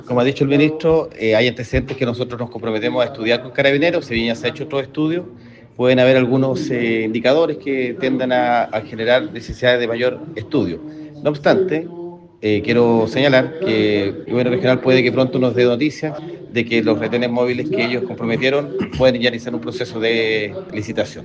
Por su parte, el delegado presidencial, Jorge Alvial, precisó que si bien, con anterioridad se han hecho estudios al respecto, puede haber ahora nuevos antecedentes que insten otros análisis.